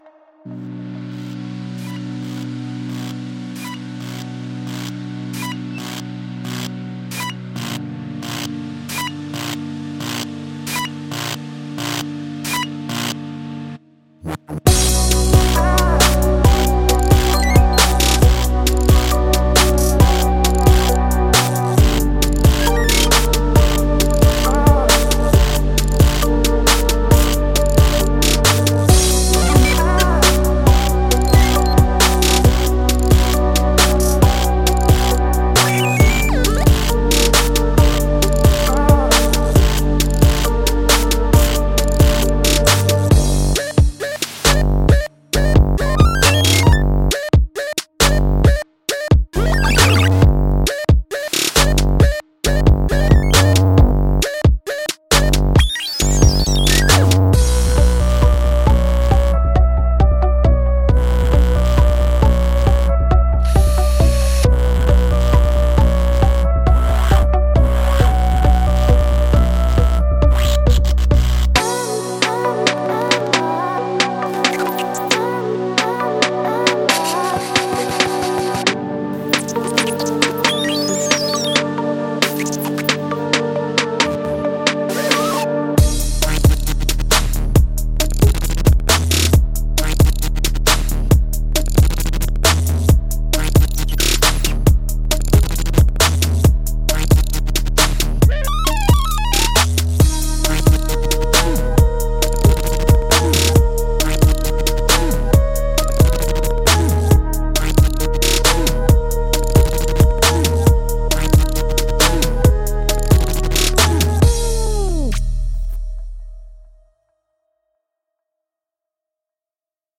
Denovaire Robofriends 是一款由奥地利的声音设计师、作曲家、表演者 Denovaire 制作的 Kontakt 5 库，它包含了各种机器人合成器的声音。这些声音来源于地球的废品场，它们被用过、破坏过、磨损过、生锈过，它们讲述了一个蒸汽动力的火箭登陆火星的复古未来时代的故事。
Denovaire Robofriends 的声音都是在模拟外围设备上精心制作的。